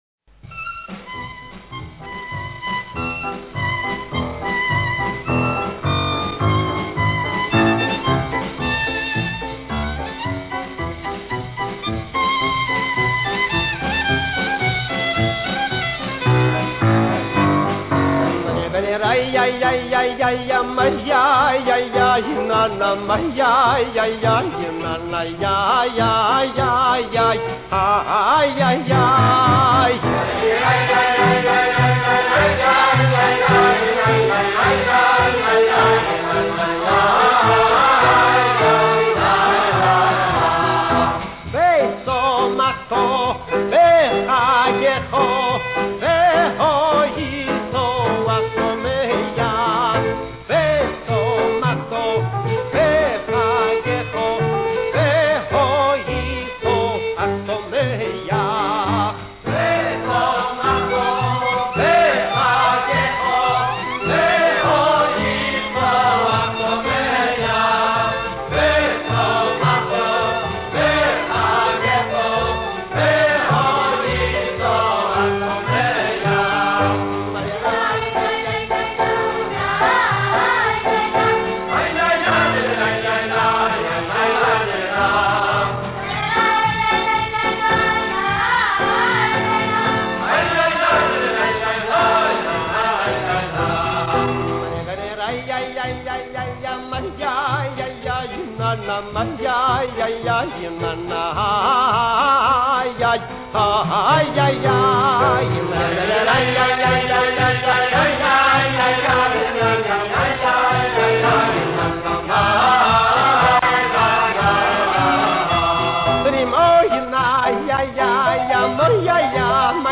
לקט ניגוני חב"ד שמחים
מי הם ילדי המקהלה?